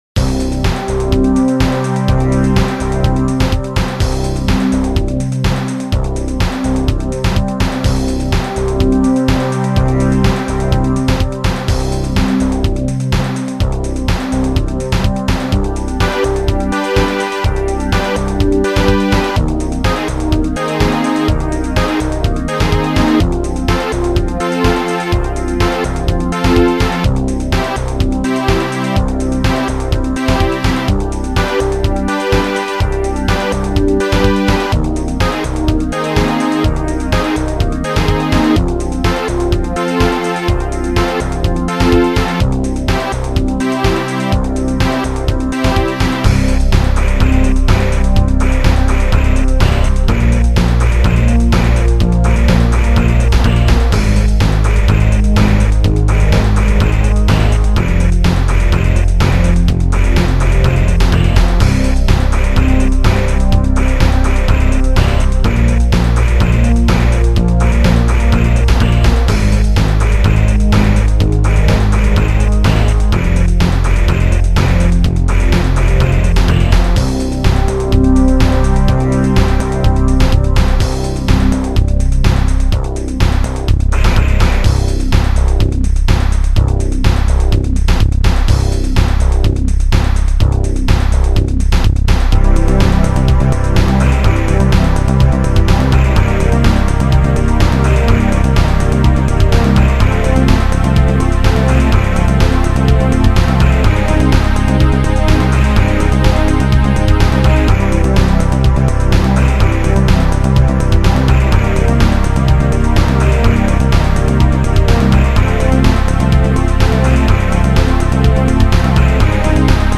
auf einem Amiga 500 & Amiga 4000.